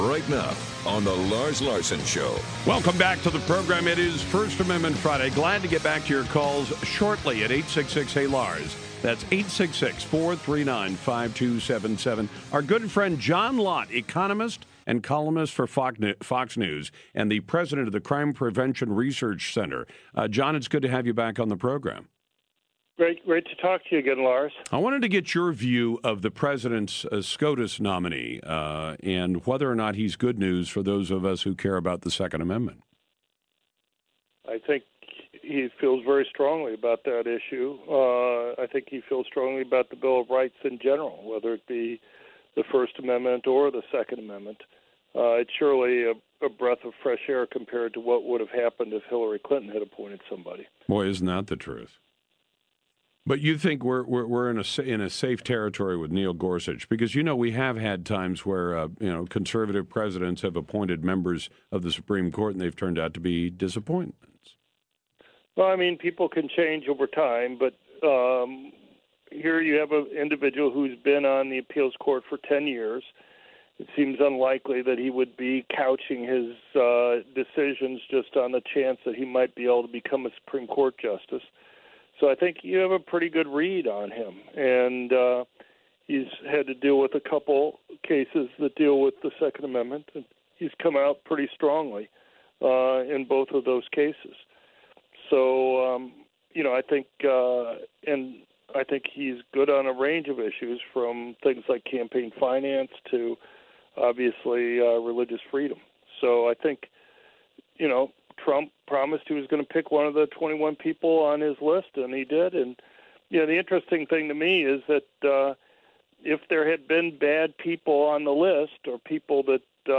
Dr. John Lott joined Lars Larson to discuss Judge Neil Gorsuch’s appointment to the Supreme Court.